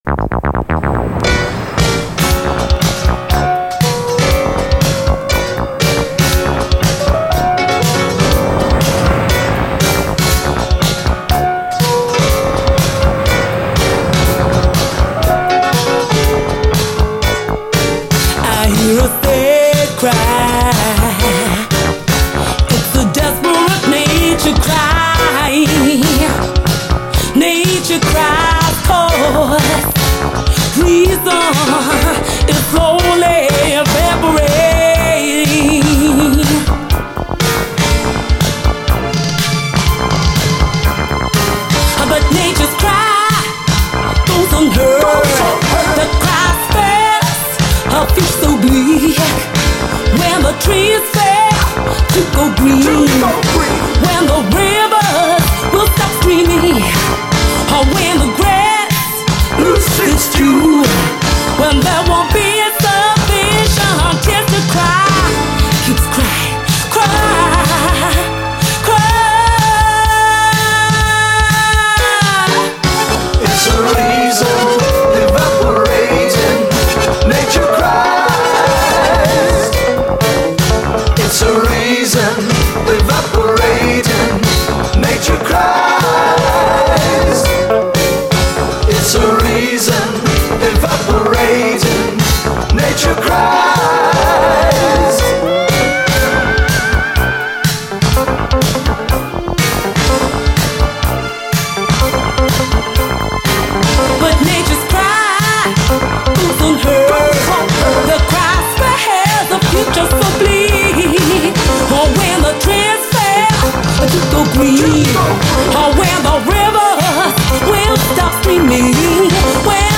SOUL, 70's～ SOUL, DISCO
ドイツ産オブスキュア・シンセ・モダン・ソウル！
ピアノが綺麗な爽快ミディアム・ソウル！